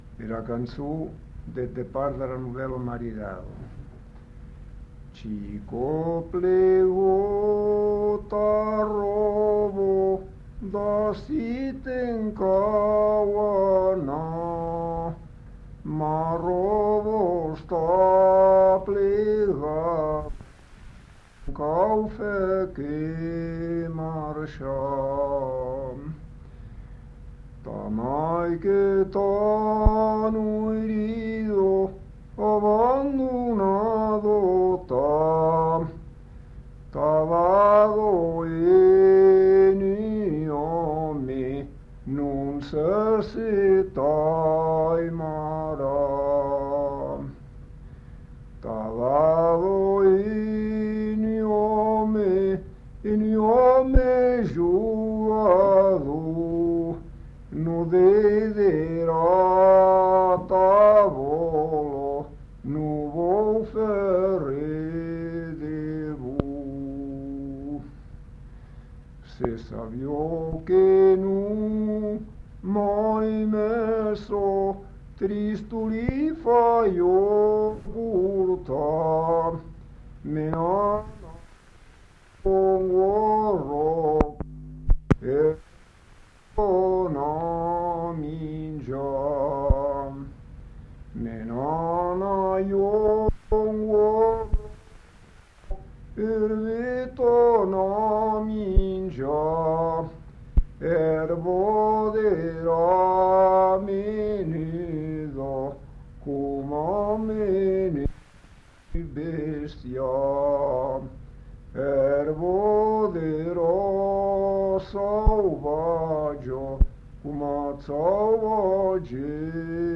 Aire culturelle : Couserans
Genre : chant
Effectif : 1
Type de voix : voix d'homme
Production du son : chanté